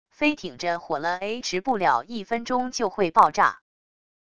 飞艇着火了a持不了一分钟就会爆炸wav音频生成系统WAV Audio Player